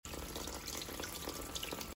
タカラベルモントの東京ショールーム。
シャンプー台に湯の滝が現れる！
「音」「頭部への刺激」「ぬくもり」の3点で本当に気持ちよくなる感じでした。
ちなみに音が最高なので、音源も置いておきますね。